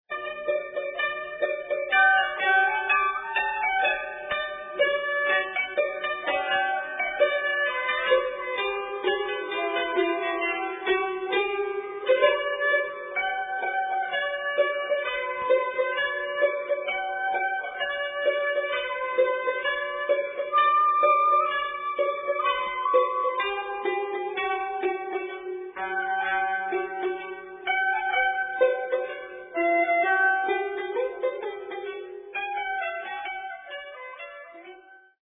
Shamisen, Koto